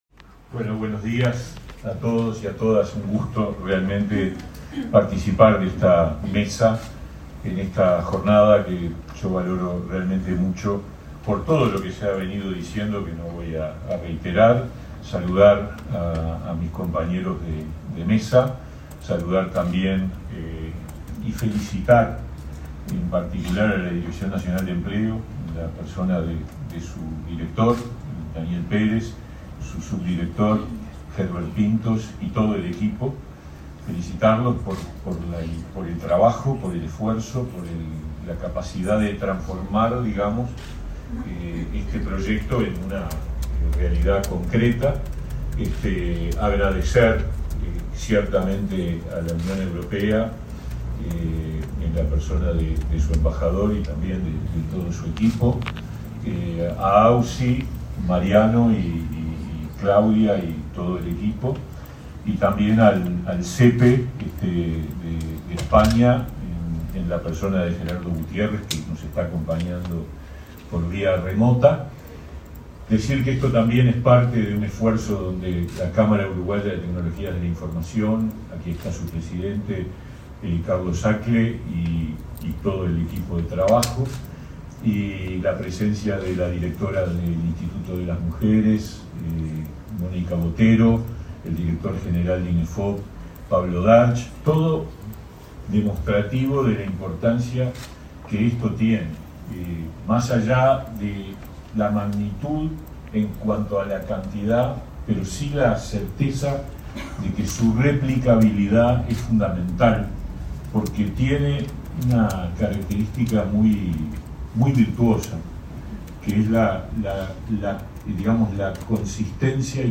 Palabras del ministro de Trabajo, Pablo Mieres
Palabras del ministro de Trabajo, Pablo Mieres 11/10/2023 Compartir Facebook X Copiar enlace WhatsApp LinkedIn El ministro de Trabajo, Pablo Mieres. participó, este miércoles 11 en Montevideo, de un seminario sobre formación profesional y empleo en el sector de las tecnologías de la información.